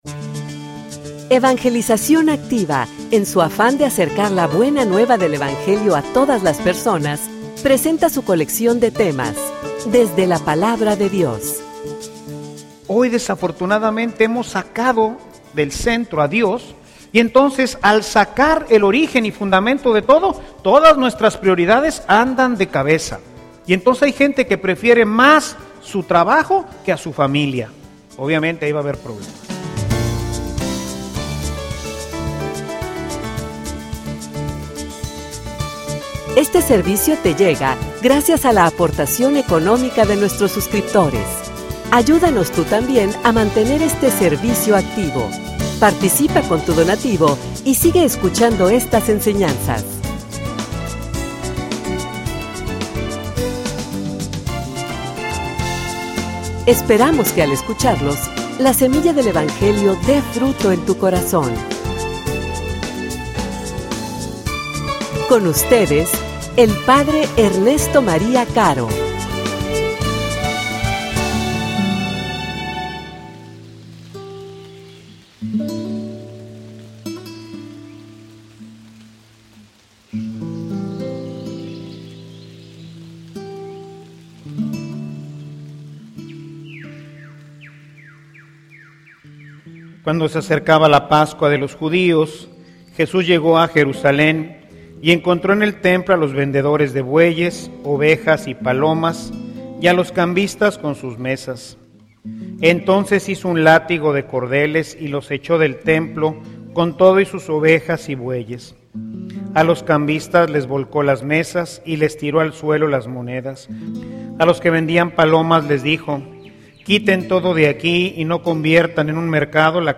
homilia_Pon_a_Dios_al_centro_de_tu_vida.mp3